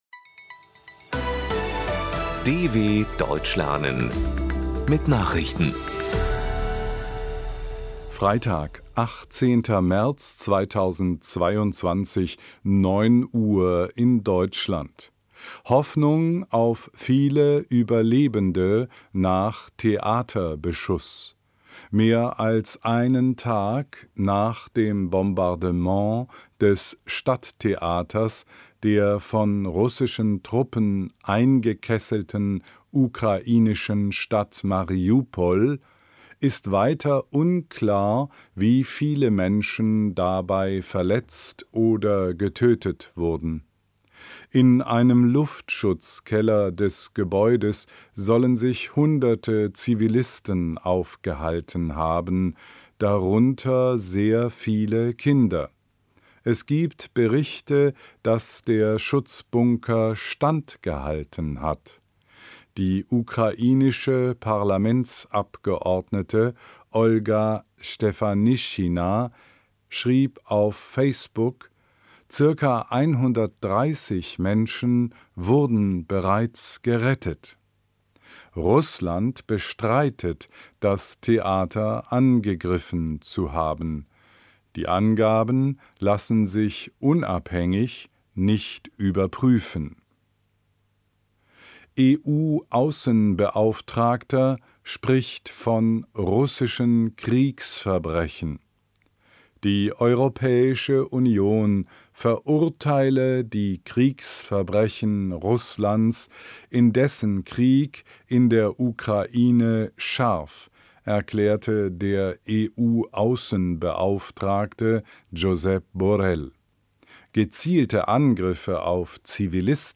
Langsam gesprochene Nachrichten
Trainiere dein Hörverstehen mit den Nachrichten der Deutschen Welle von Freitag – als Text und als verständlich gesprochene Audio-Datei.
Nachrichten von Freitag, 18.03.2022 – langsam gesprochen | MP3 | 6MB